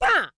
Cat Attack Sound Effect
Download a high-quality cat attack sound effect.
cat-attack-4.mp3